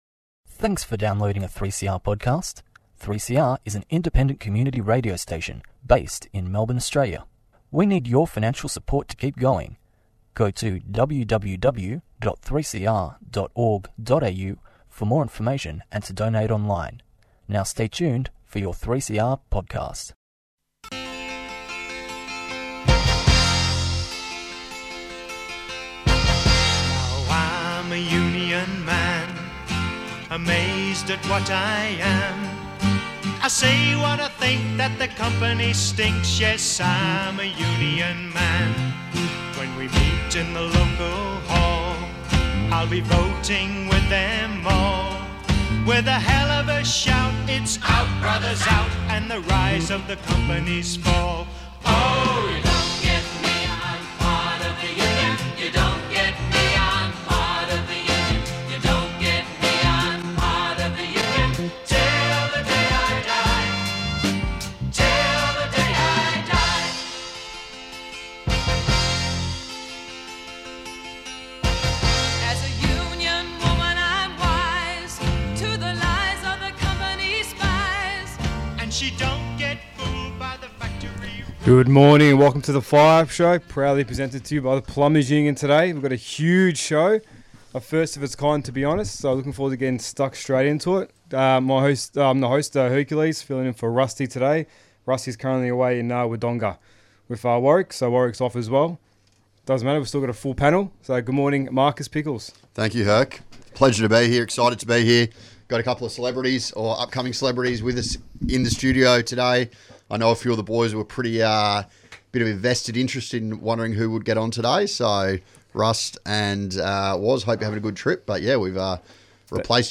Tweet Fire Up Thursday 6:30am to 7:00am The Plumbers Union weekly radio show.